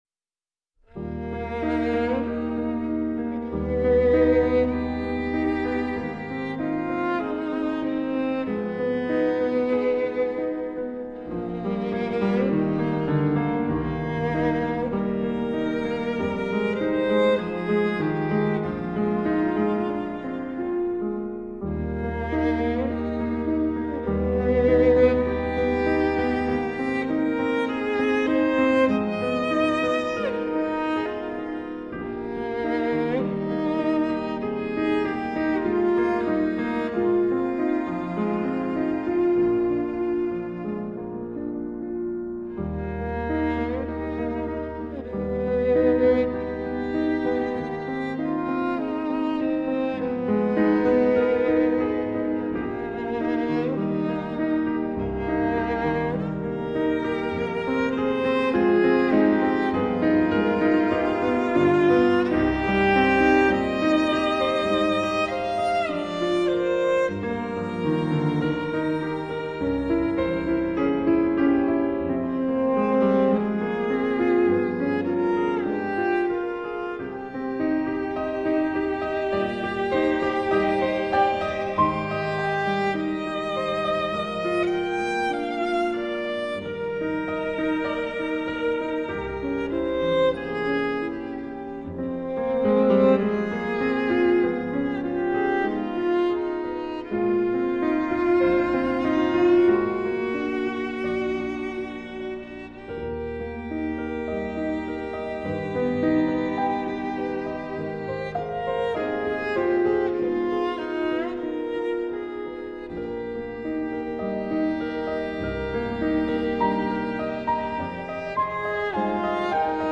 流派: 古典
クラシックのフィールドをベースに、さまざまな分野でマルチ・プレイヤーとしての活躍が衆目を引くふたりの女性音楽家の競演。